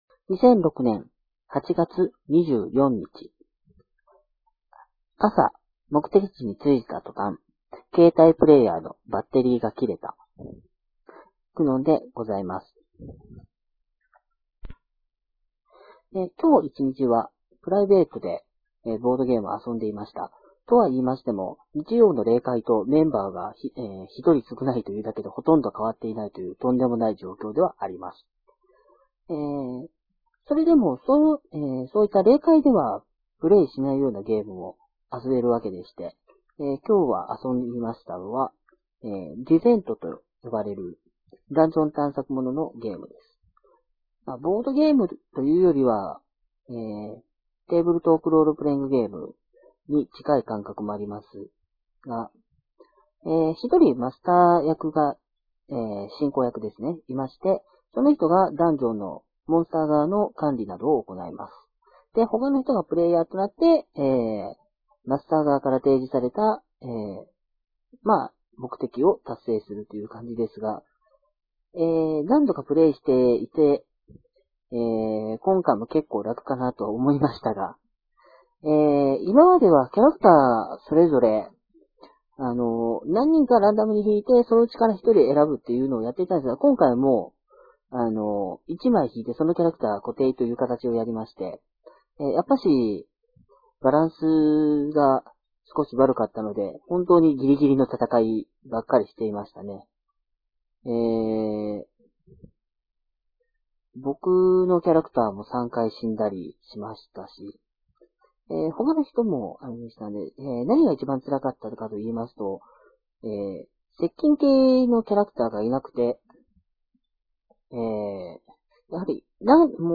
音質が悪くて聞きづらいです。